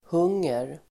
Uttal: [h'ung:er]